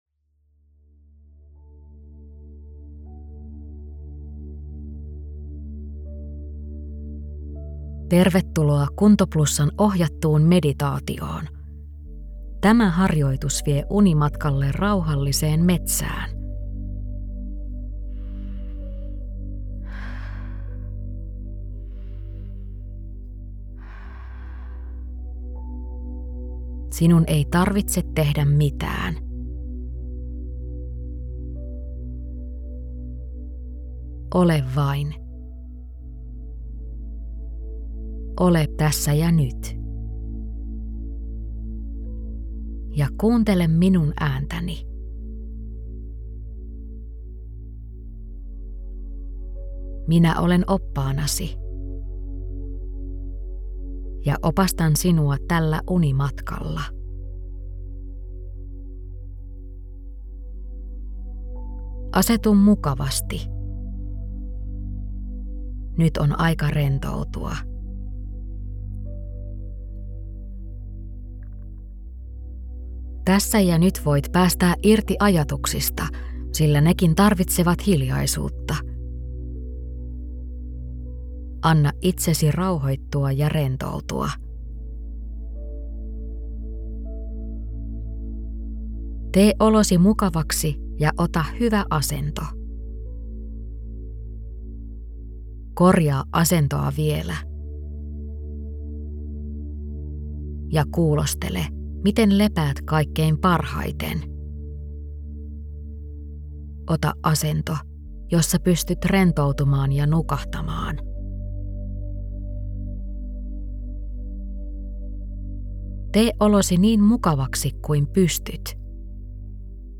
Ohjattu unimeditaatio: mielikuvamatka luontoon
Harjoitus on ohjattu alusta loppuun – sinun tarvitsee vain kuunnella.
Viimeiset viisi minuuttia eivät sisällä enää selostusta, vaan ne ovat vain rauhallisen ääniraidan jatkoa.